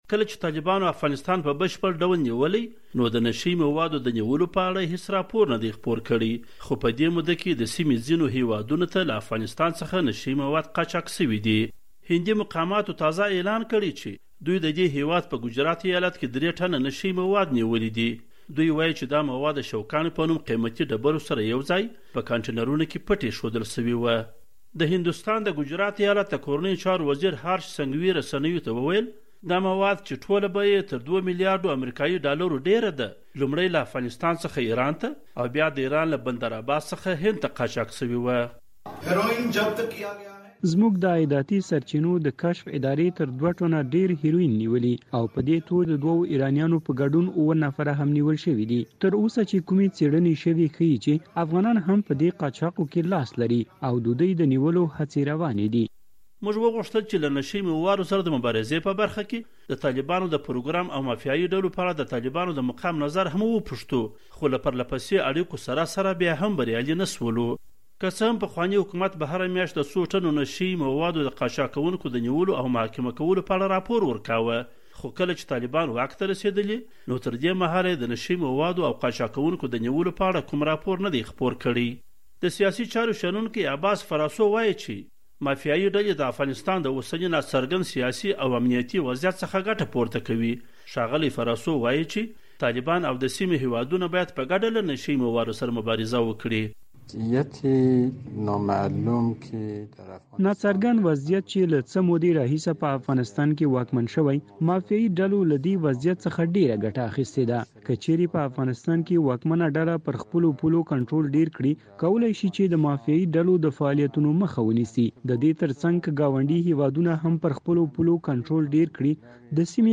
راپور